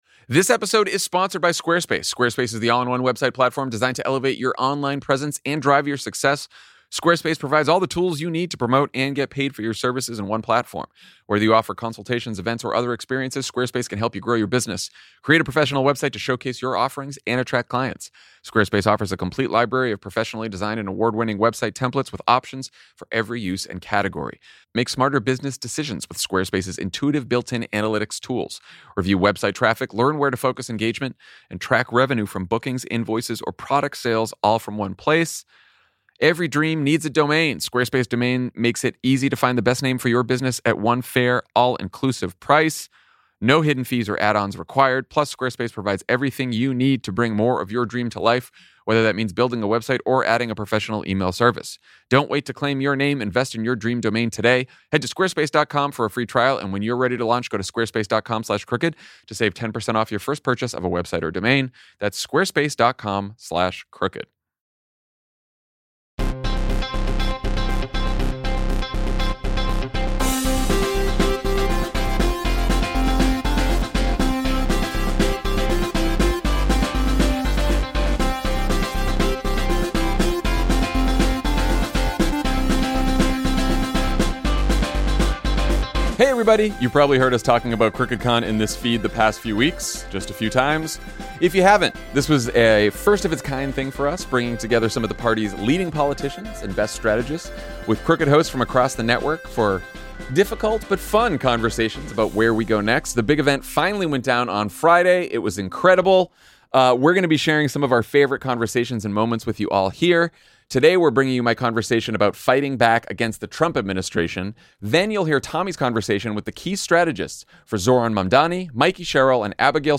How Do We Push Back on Authoritarianism? (Crooked Con)